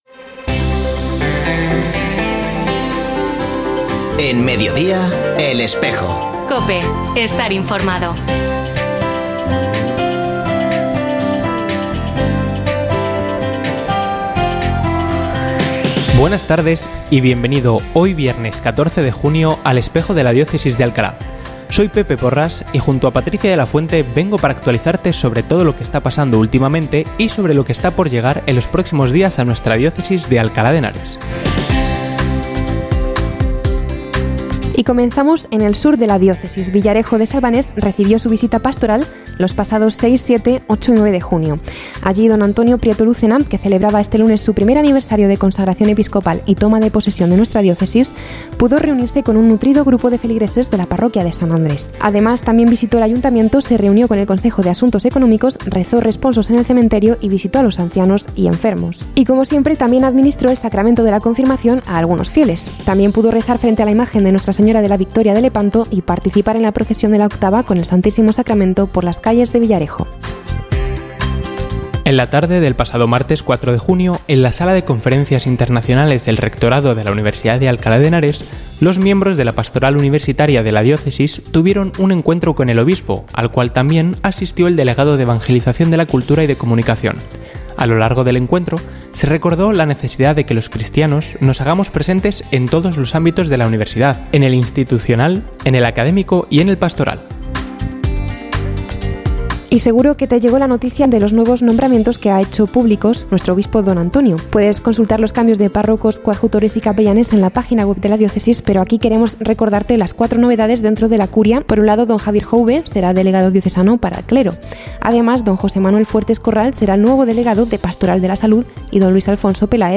Ofrecemos el audio del programa de El Espejo de la Diócesis de Alcalá emitido hoy, 14 de junio de 2024, en radio COPE. Este espacio de información religiosa de nuestra diócesis puede escucharse en la frecuencia 92.0 FM, todos los viernes de 13.33 a 14 horas.